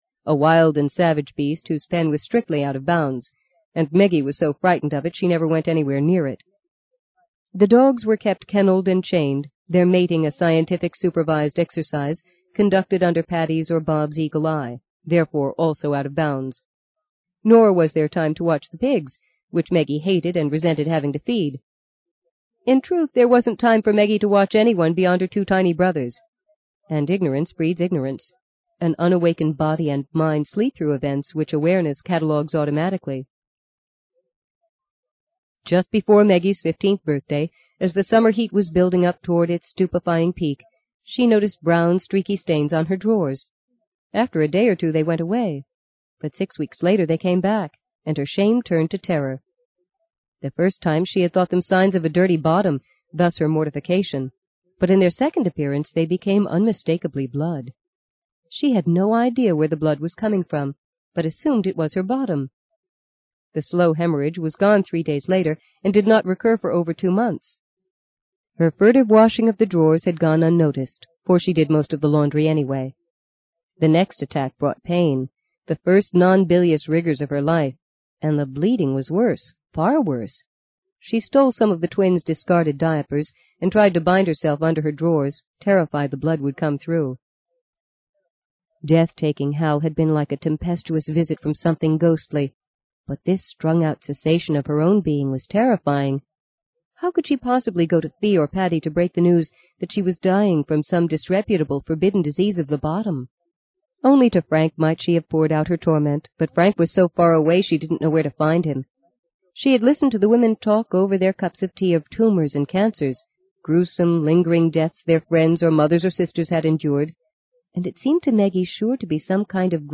在线英语听力室【荆棘鸟】第六章 19的听力文件下载,荆棘鸟—双语有声读物—听力教程—英语听力—在线英语听力室